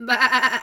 sheep_2_baa_calm_04.wav